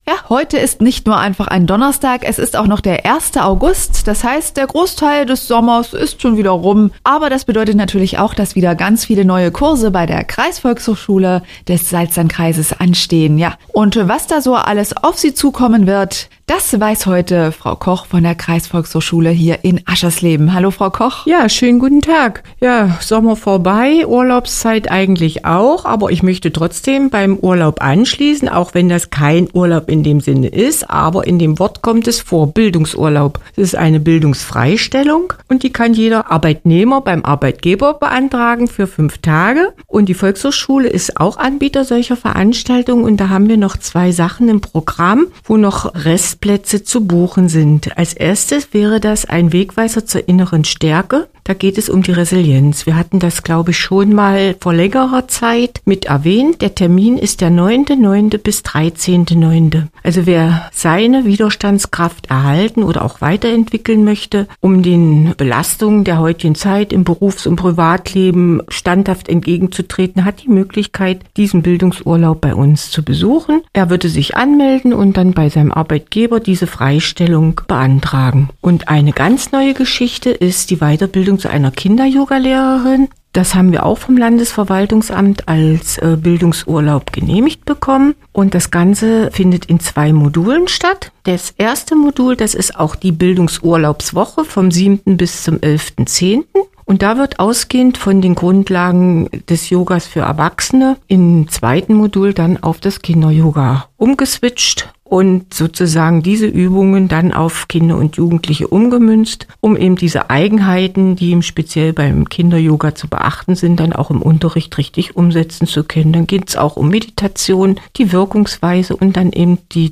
Hörbeitrag vom 1. August 2024